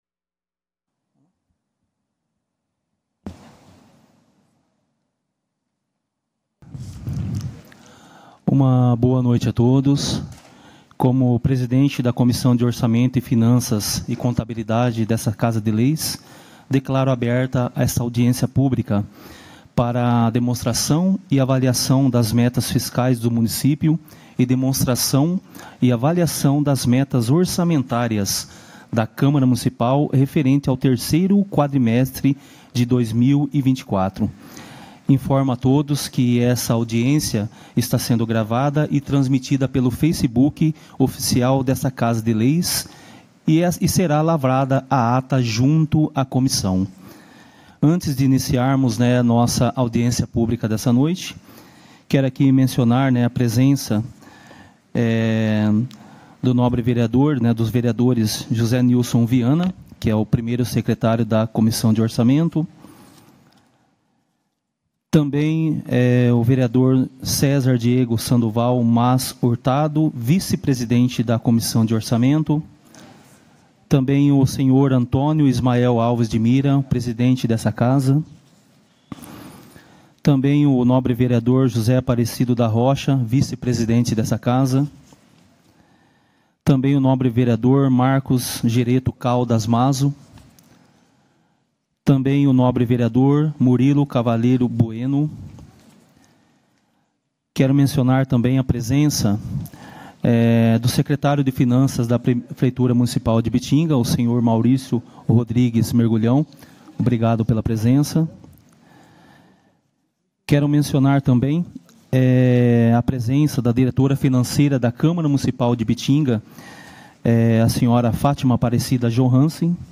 Audiências Públicas